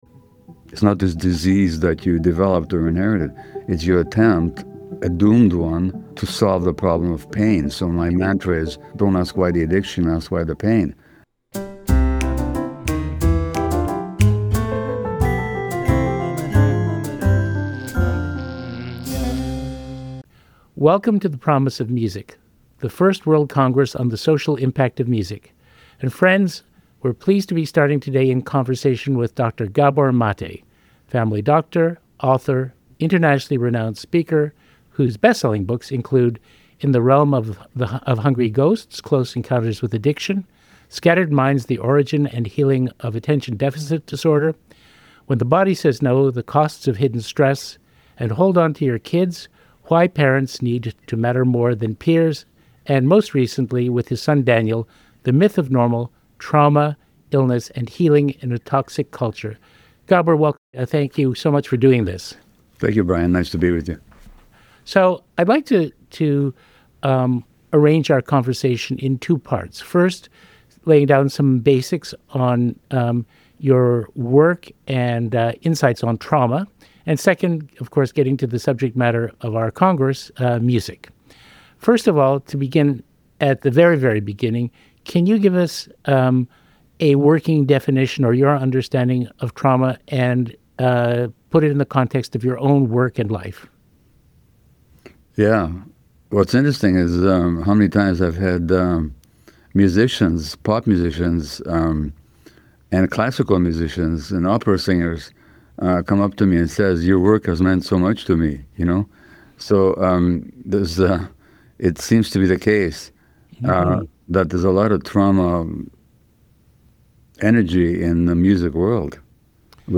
Join us for part one of a revelatory conversation (presented at The Promise of Music - The First World Congress on the Social Impact of Music presented by The Glenn Gould Foundation in October 2025).